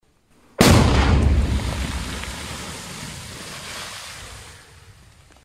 Звуки петард